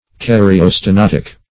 Search Result for " karyostenotic" : The Collaborative International Dictionary of English v.0.48: Karyostenotic \Kar`y*o*ste*not"ic\, a. (Biol.)